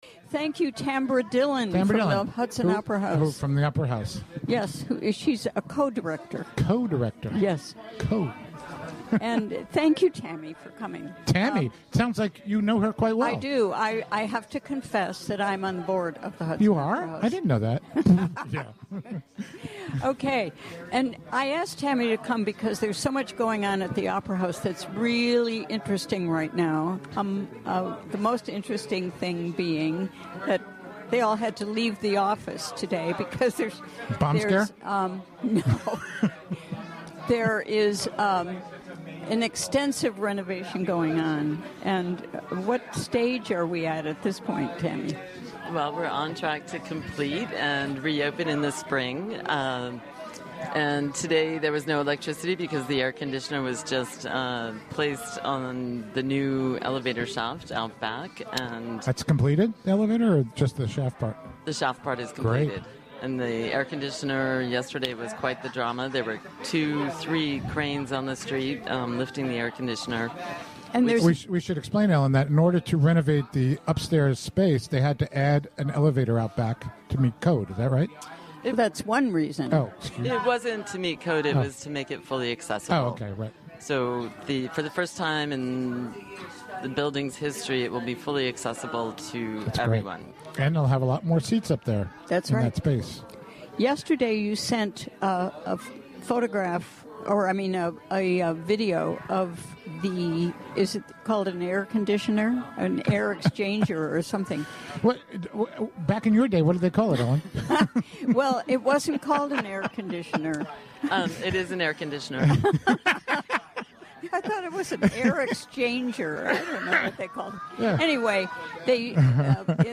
6pm Special remote broadcast from Spotty Dog Books & A...
Recorded during the WGXC Afternoon Show Thursday, October 20, 2016.